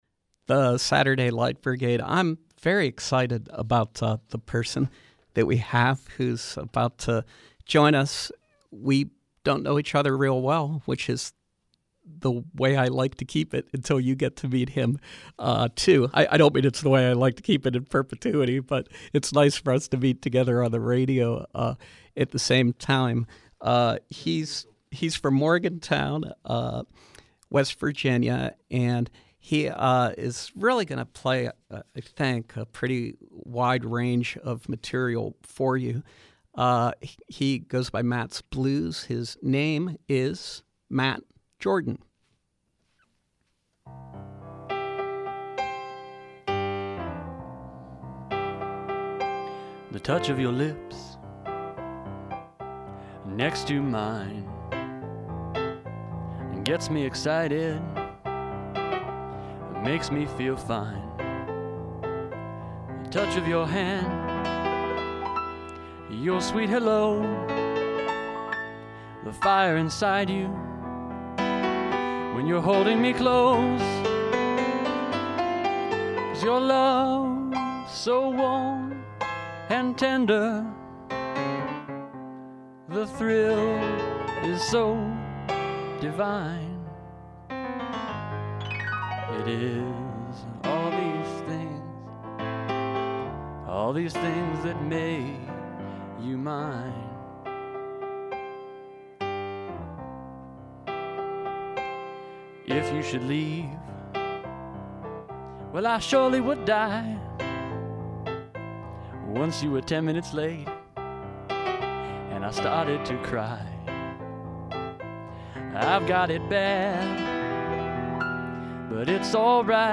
Live Music
Blues, funk, gospel, and New Orleans jazz
pianist and vocalist